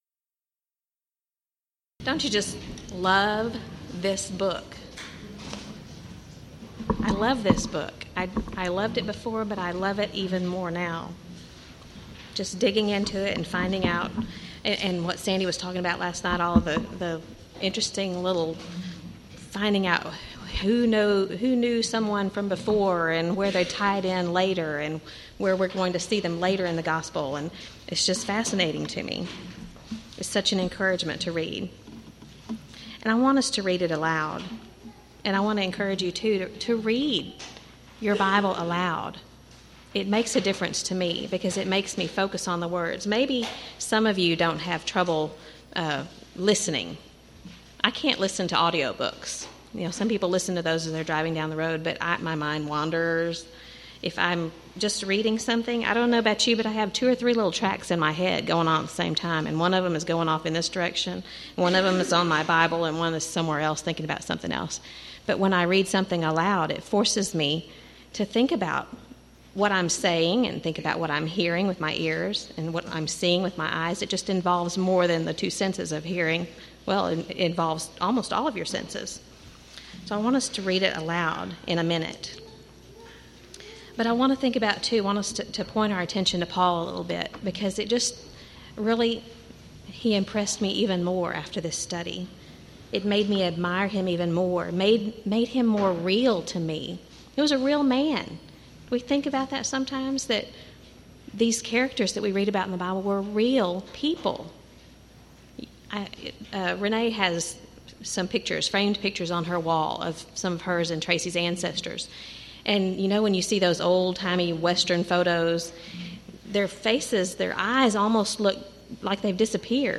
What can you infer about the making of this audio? Event: 1st Annual TLC Retreat